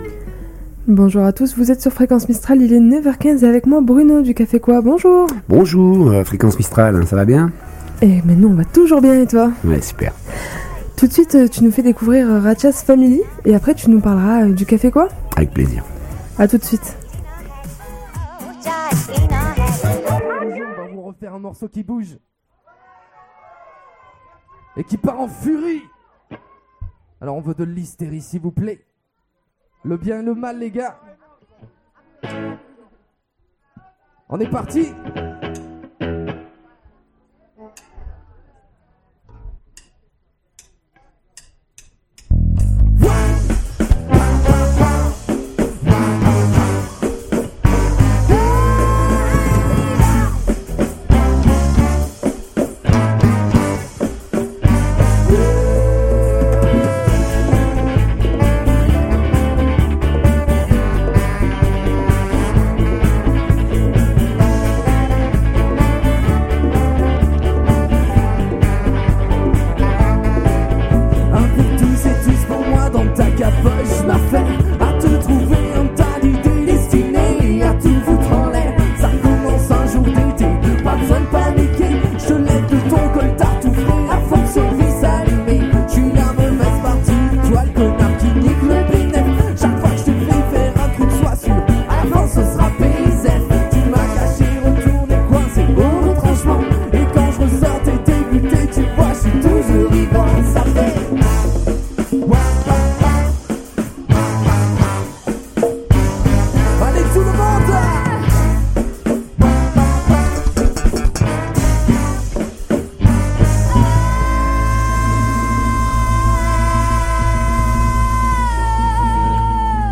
il dévoile le programme en plateau